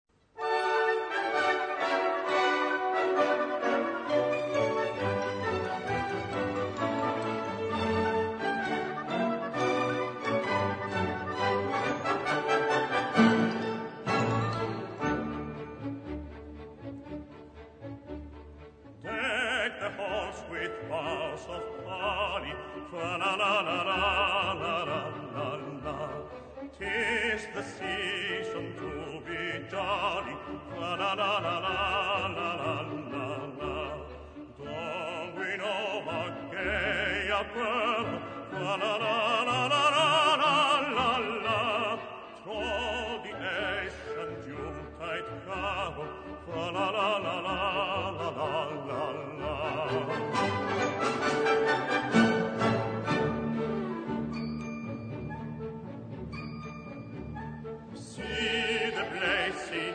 Piano & Vocal Score
key: D-major